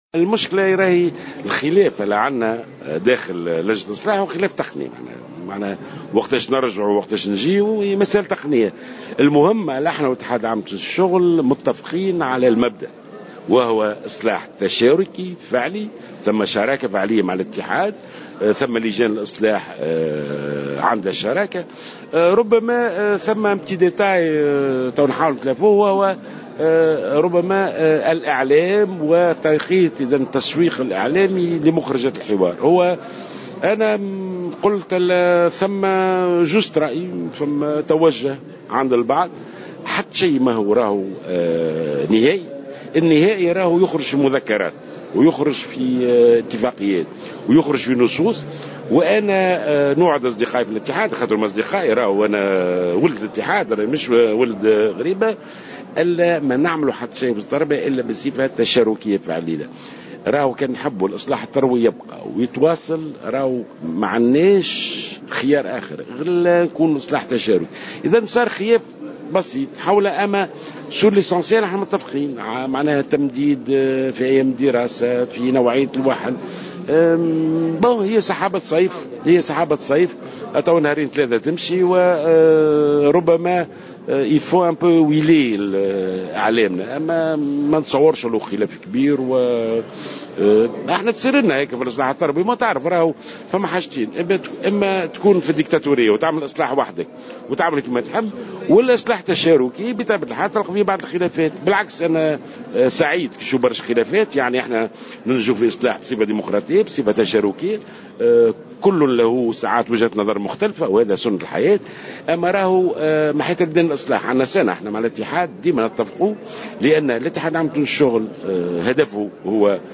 وأضاف جلول في تصريح اليوم لمراسل "الجوهرة أف أم" على هامش حفل تكريم المندوبين الجهويين للتربية بسوسة أن الخلاف داخل لجنة الإصلاح المتعلق بتاريخ العودة المدرسية خلاف تقني، مؤكدا أن عملية الإصلاح التربوي تتم بصفة تشاركية.